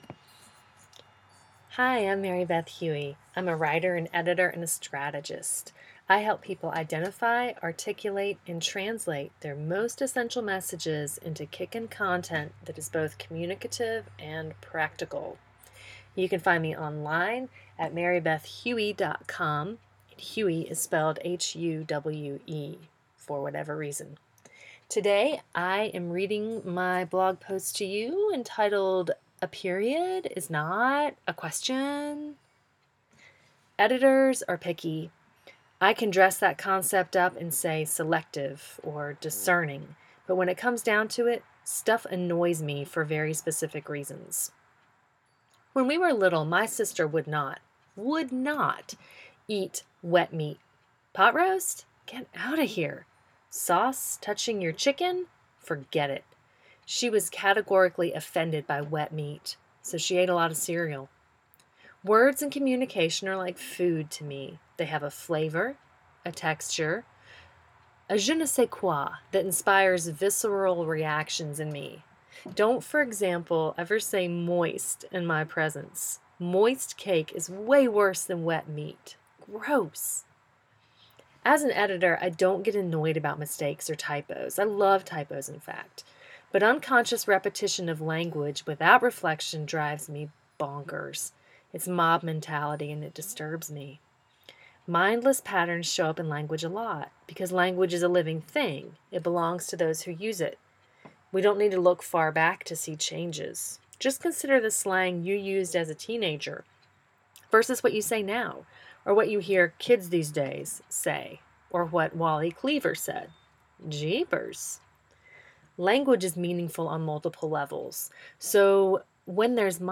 Click the play arrow to hear me read this essay to you: